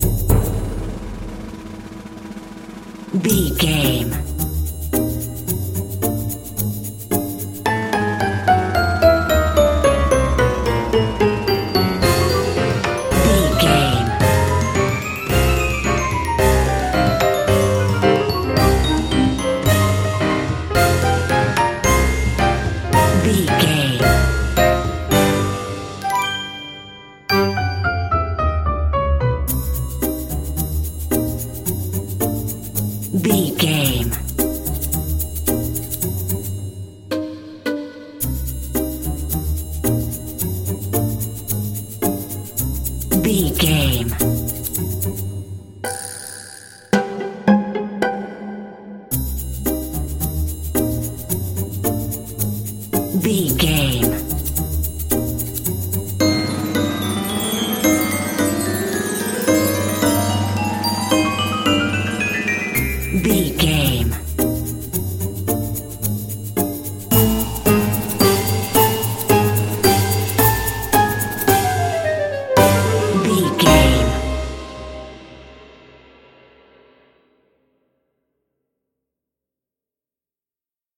Aeolian/Minor
percussion
strings
silly
circus
goofy
comical
cheerful
perky
Light hearted
quirky